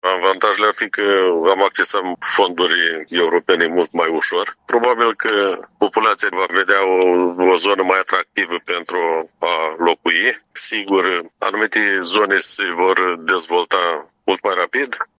Primarul comunei, Dan Niță, a precizat că dacă acest sondaj de opinie va fi favorabil transformării comunei în oraș, atunci vor putea fi accesate mai ușor fonduri europene și guvernamentale.